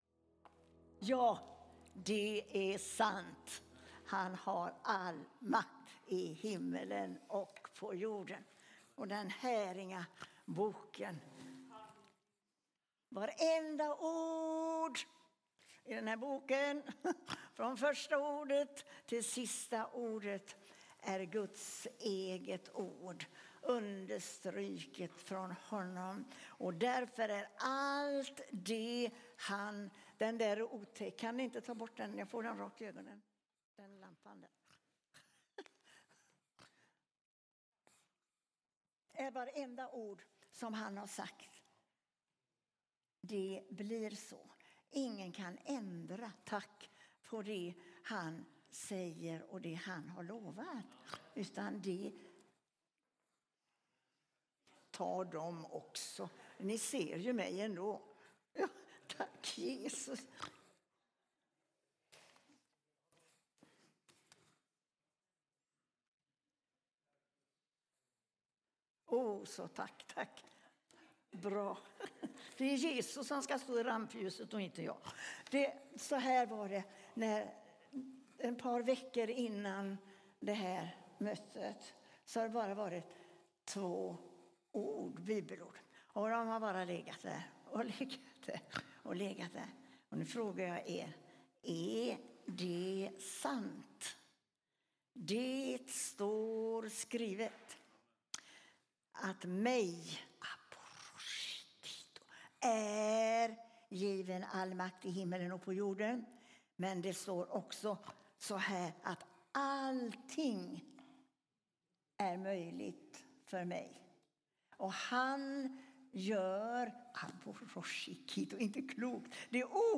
Baptistkyrkan Karlskoga söndag 11 januari 2026